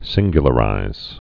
(sĭnggyə-lə-rīz)